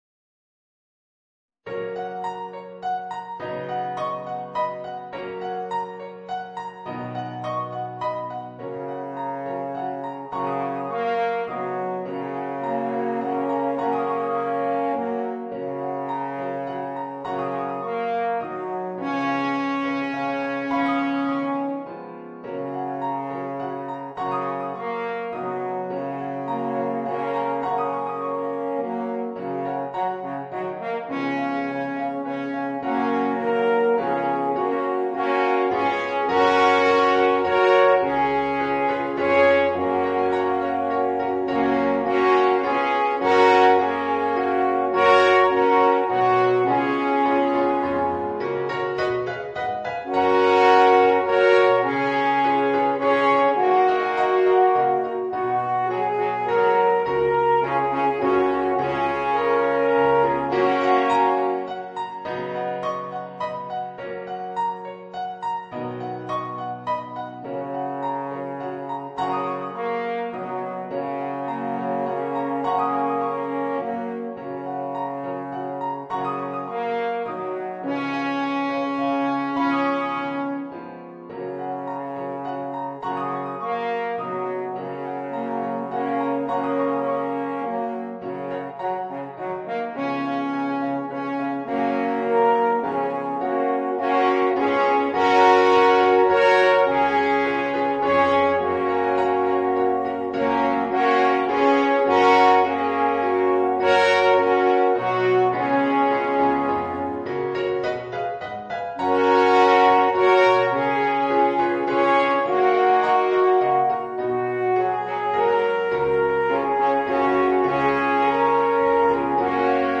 Voicing: 3 Alphorns and Piano